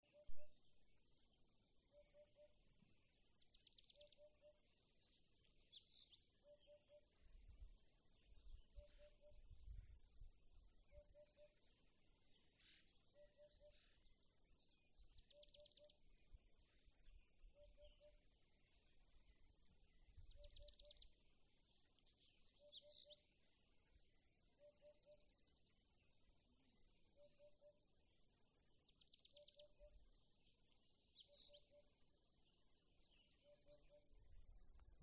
Hoopoe, Upupa epops
StatusSinging male in breeding season
Notes/Nu te tas pūpina bieži, tā, ka istabā dzird.